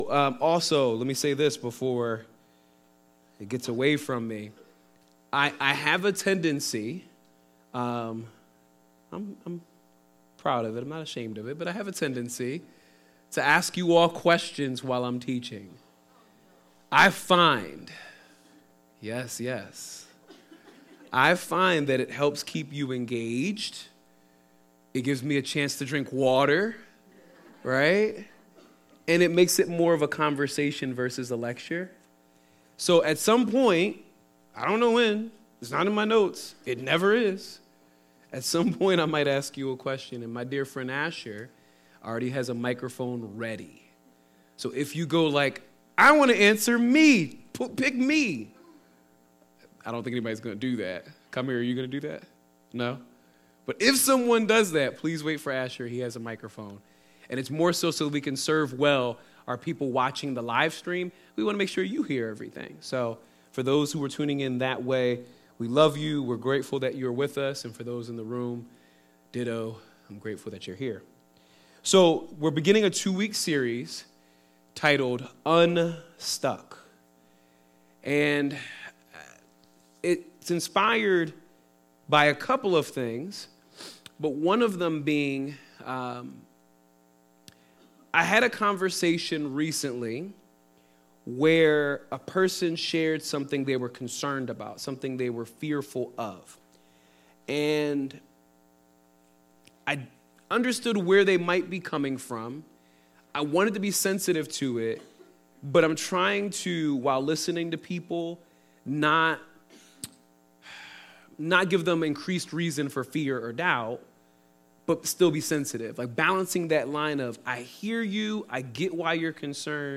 Sermons | Word of Grace Fellowship